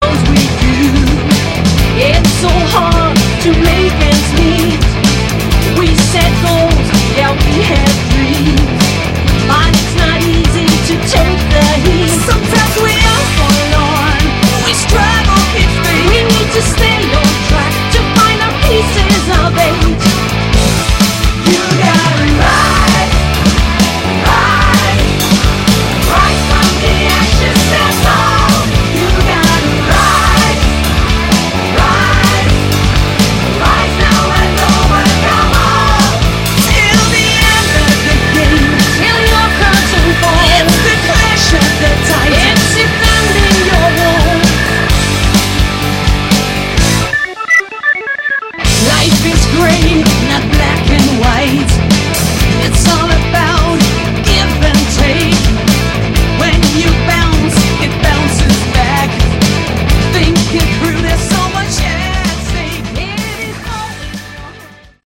Category: Melodic Rock
vocals
bass
guitar
drums
keyboards